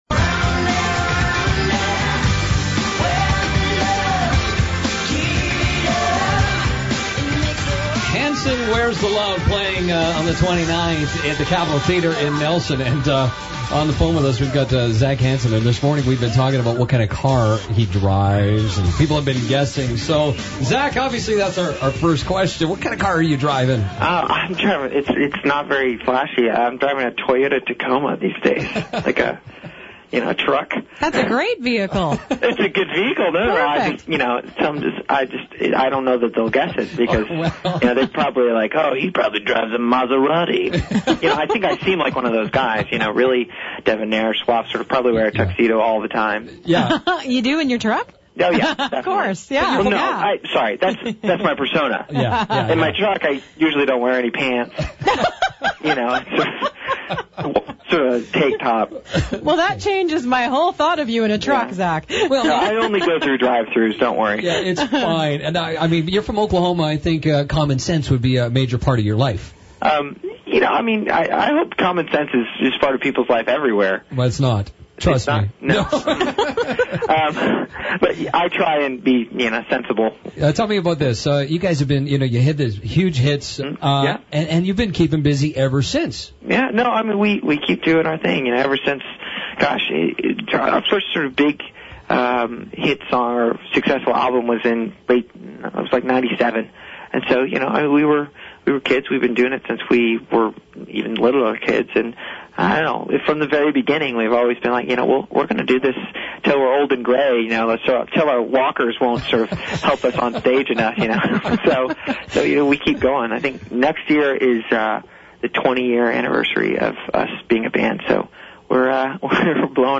Hanson Interview had People Talking
Zac Hanson called the morning show to discuss their upcoming show in Nelson.